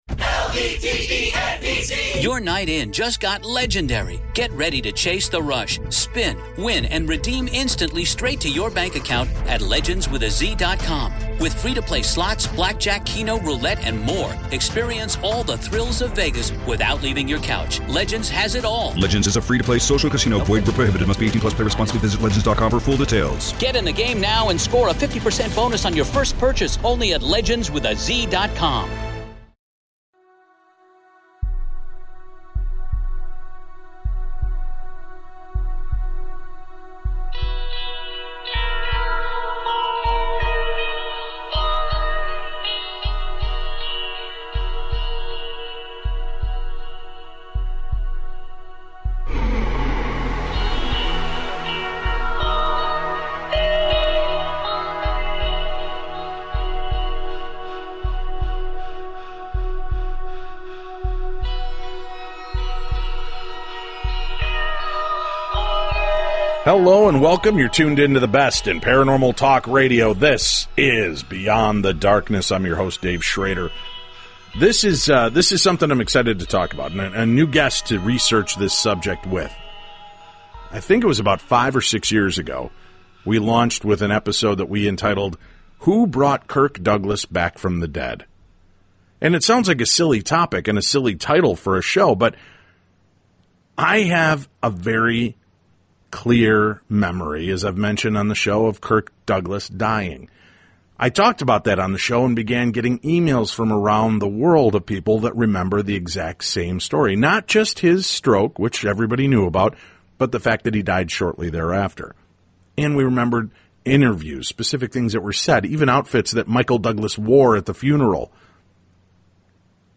Paranormal Talk Radio